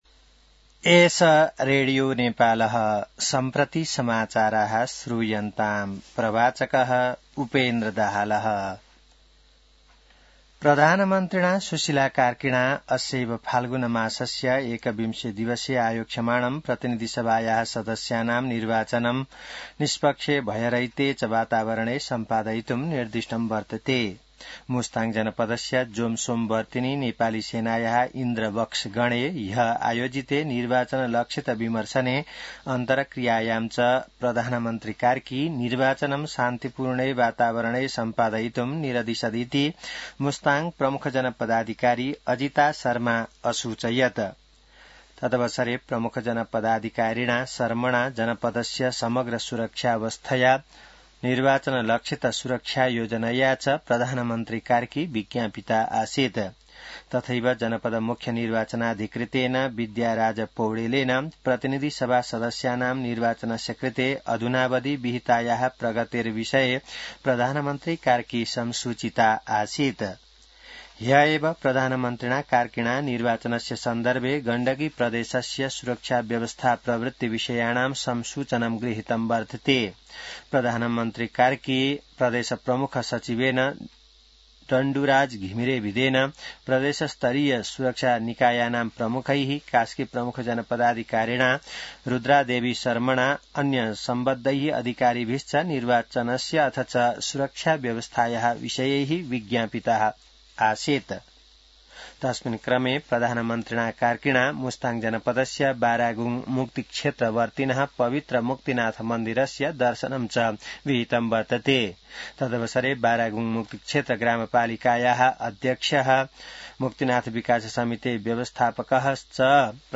संस्कृत समाचार : ९ फागुन , २०८२